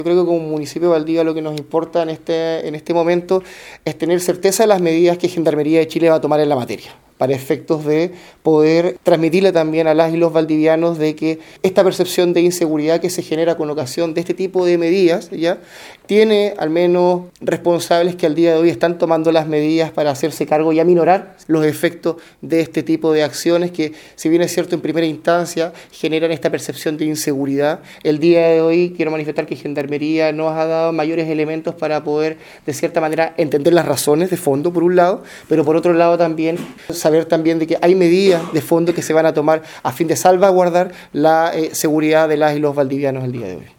Desde el municipio, el alcalde subrogante Cristian Oñate, afirmó que lo importante es que las medidas que ya se están adoptando, decanten en mayor seguridad en los habitantes de la comuna.